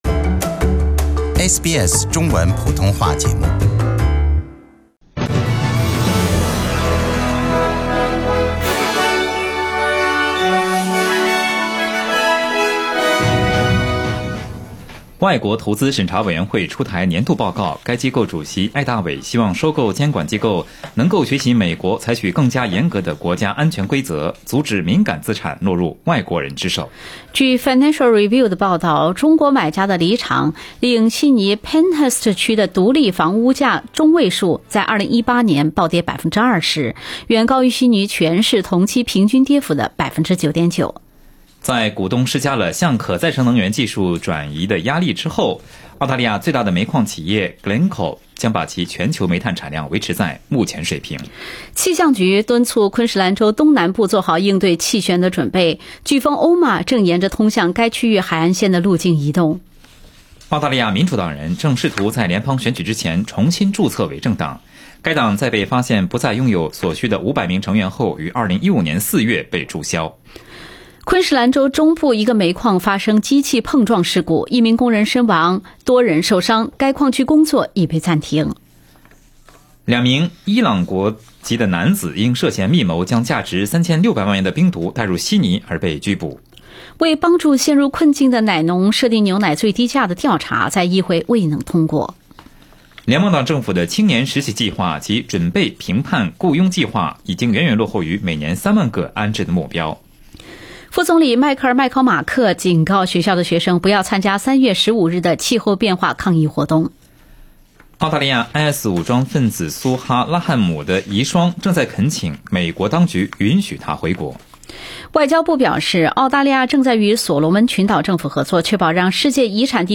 21ST FEB MORNING NEWS